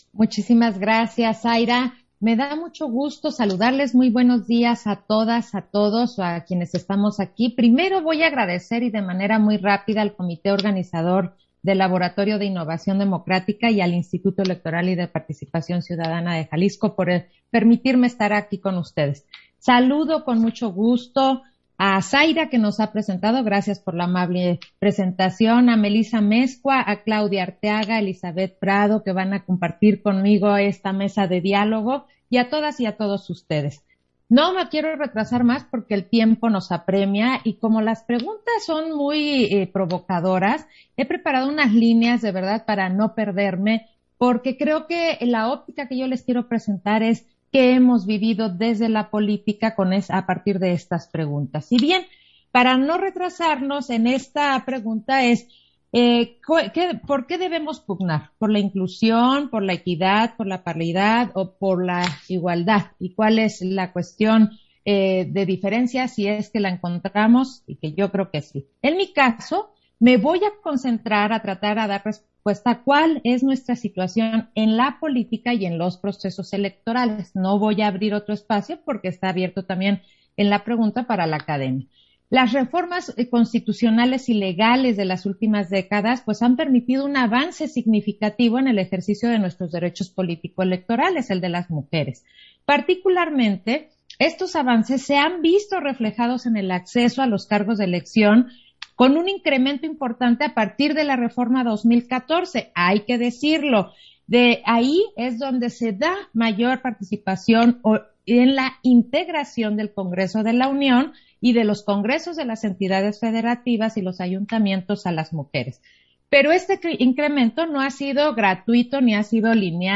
Intervención de Claudia Zavala, durante el conversatorio Desafíos jurídicos y culturales de la participación de las mujeres en la política ¿inclusión, equidad o paridad?